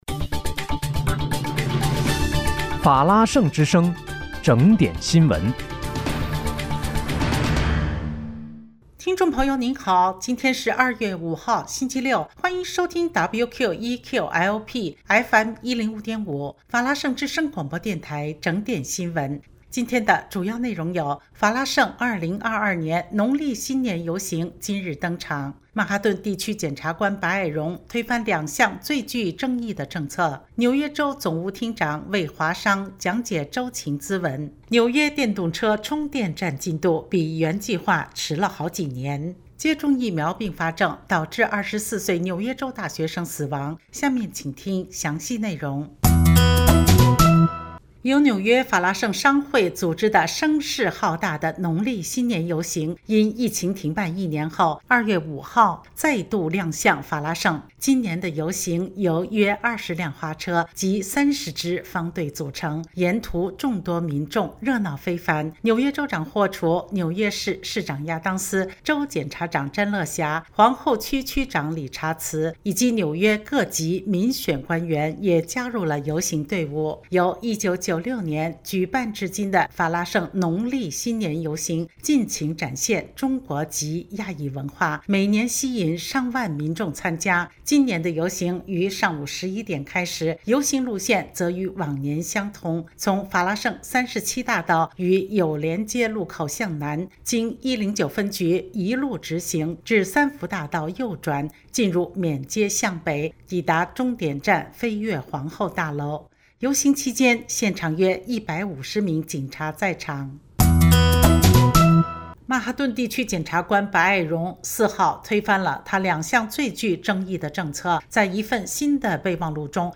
2月5日（星期六）纽约整点新闻